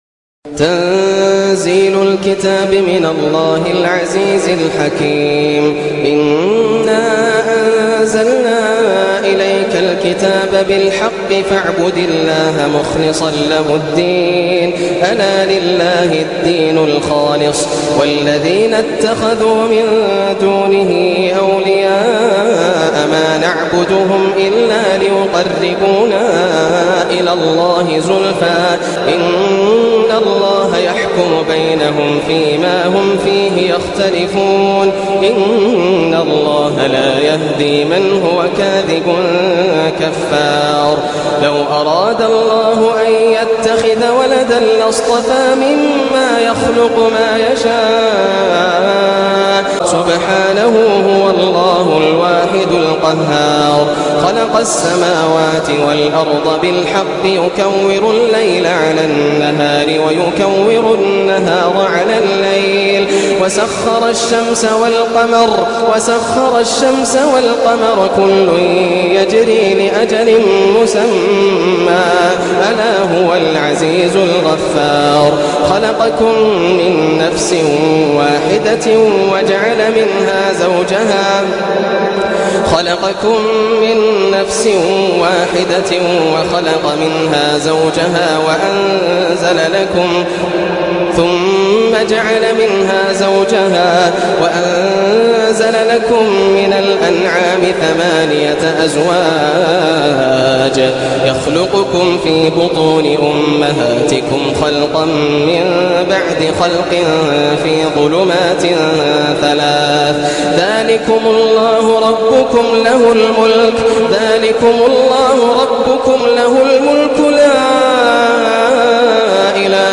سورة الزمر > السور المكتملة > رمضان 1425 هـ > التراويح - تلاوات ياسر الدوسري